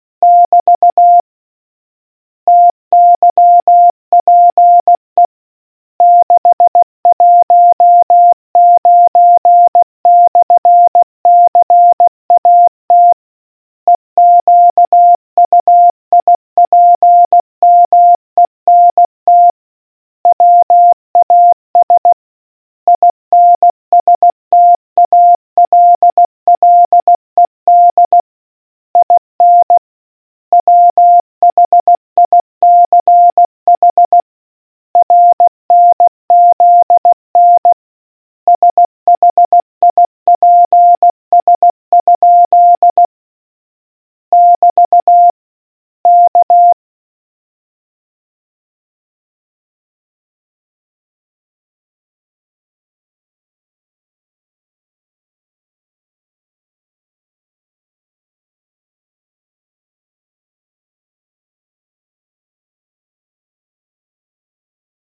Morse Question 006